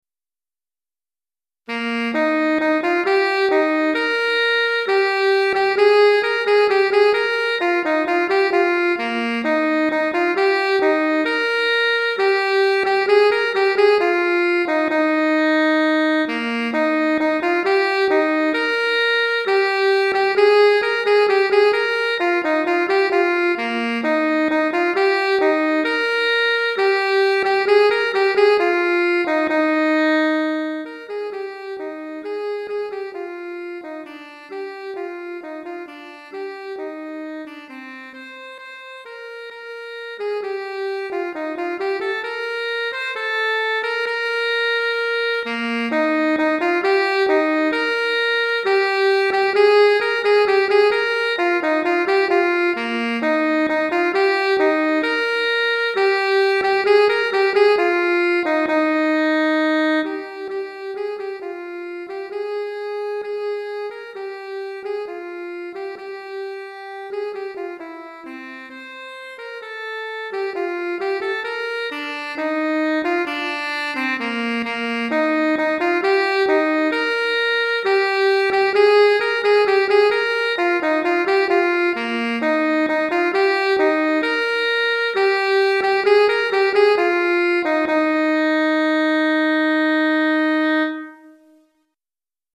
Saxophone Alto Solo